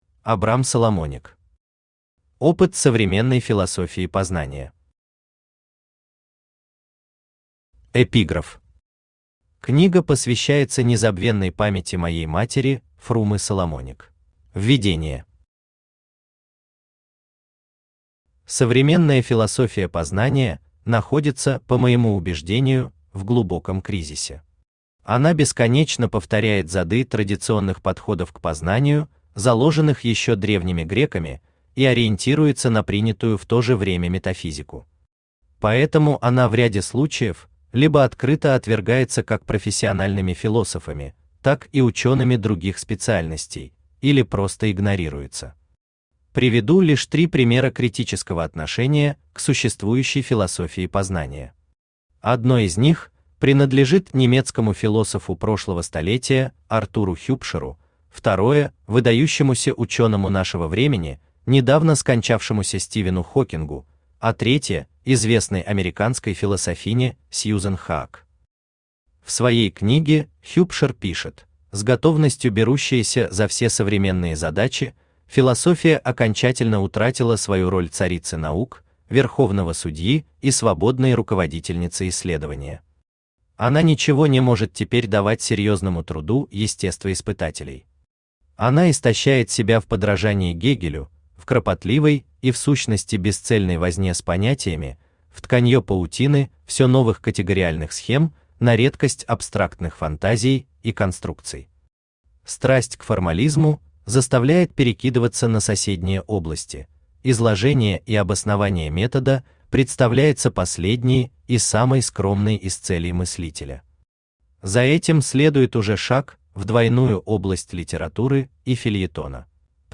Аудиокнига Опыт современной философии познания | Библиотека аудиокниг
Aудиокнига Опыт современной философии познания Автор Абрам Бенцианович Соломоник Читает аудиокнигу Авточтец ЛитРес.